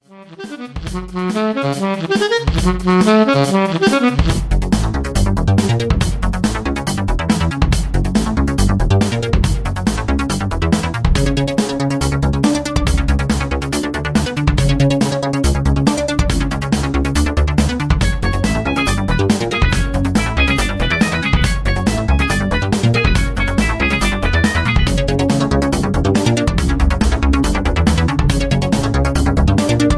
freetime electric song